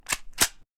reloading.mp3